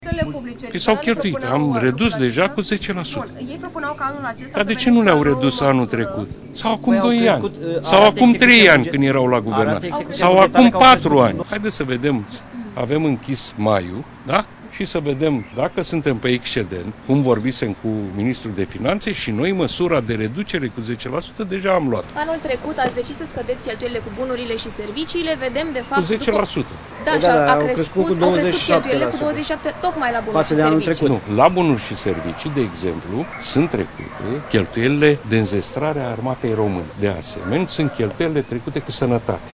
Pe de altă parte, premierul Marcel Ciolacu s-a arătat enervat de faptul că liderii PNL cer în spațiul public o reducere a cheltuielilor, având în vedere lipsa de bani de la Buget și cheltuielile tot mai mari ale instituțiilor publice:
03ian-13-Ciolacu-nervos-cu-banii.mp3